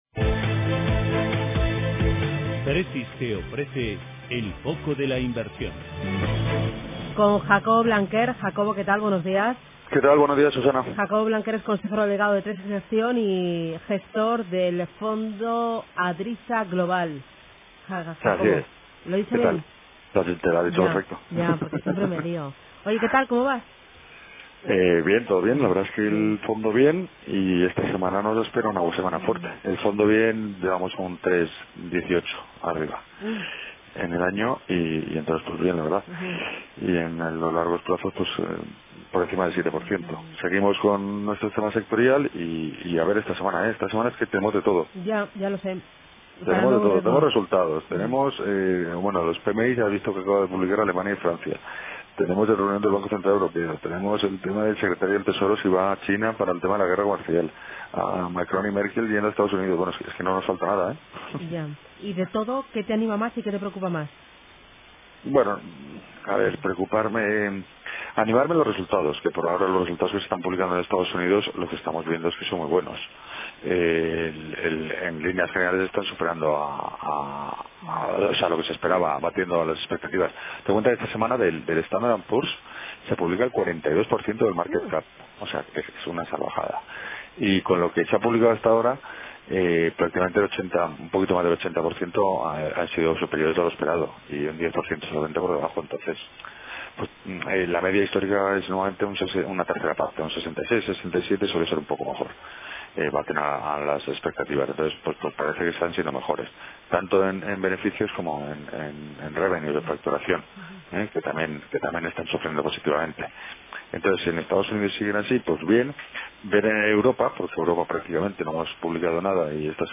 En Radio Intereconomía todas las mañanas nuestros expertos analizan la actualidad de los mercados.